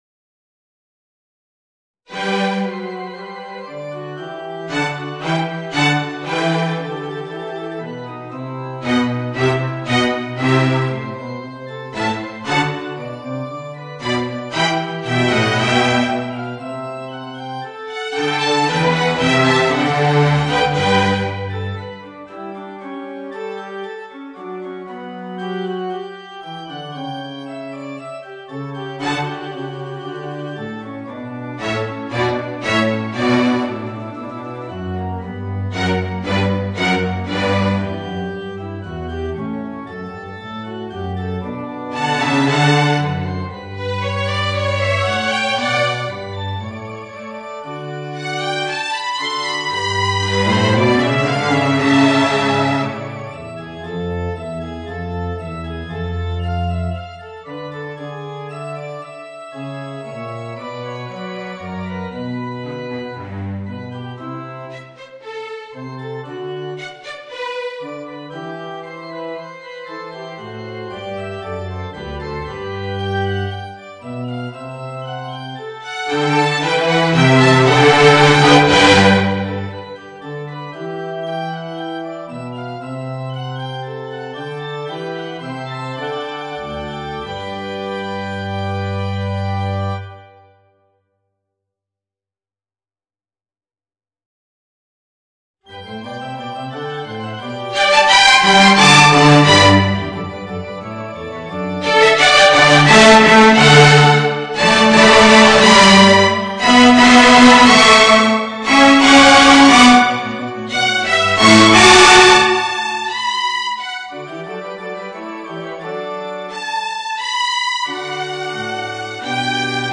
Voicing: 2 Violins, Violoncello and Piano